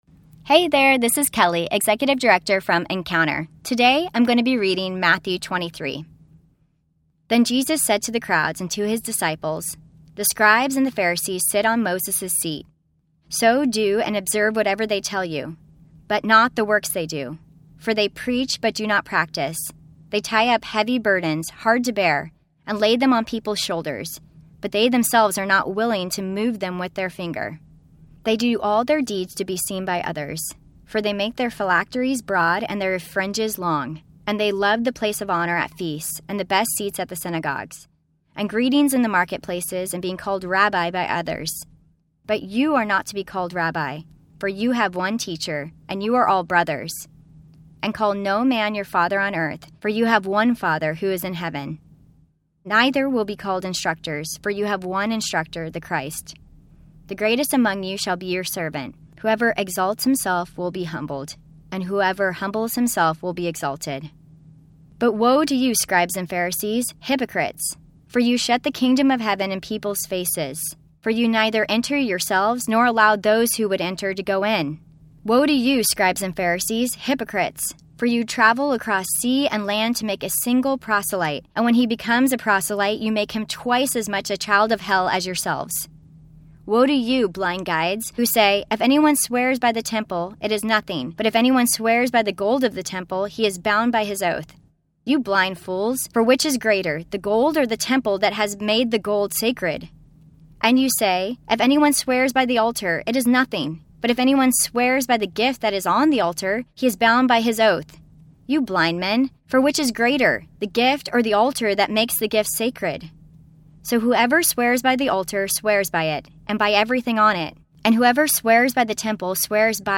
New Testament Bible Reading Plan – Audio Version